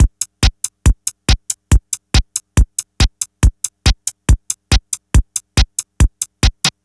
NRG 4 On The Floor 043.wav